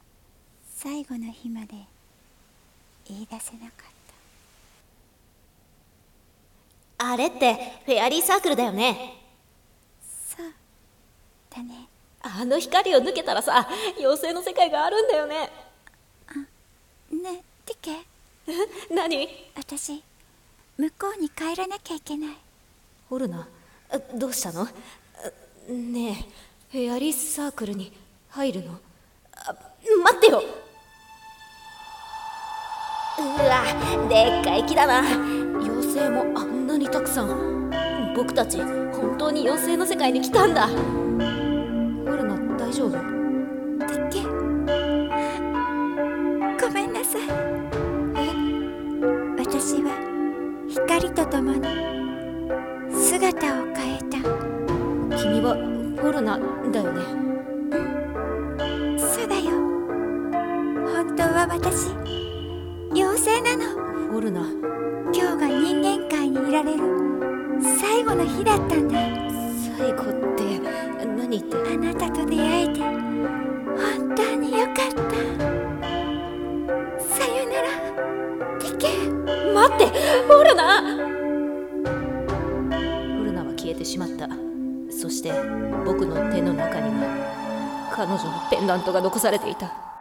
【声劇コラボ用】フェアリーサークル【2人声劇】